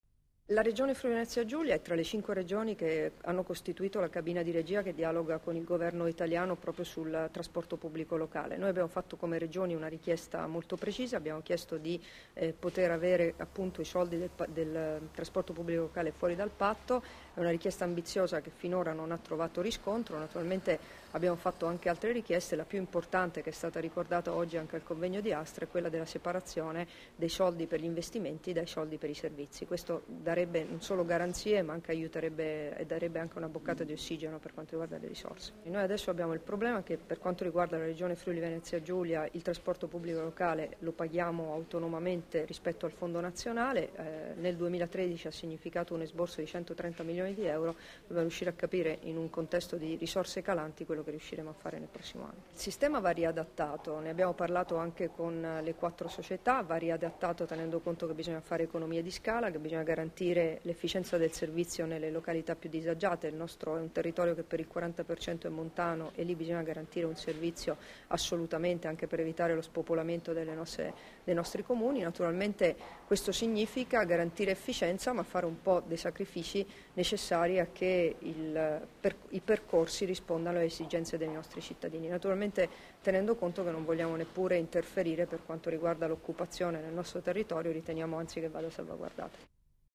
Dichiarazioni di Debora Serracchiani (Formato MP3) [1440KB]
rilasciate a margine del convegno nazionale "Un'esperienza al confine" sul TPL-Trasporto Pubblico Locale, a Gorizia il 18 ottobre 2013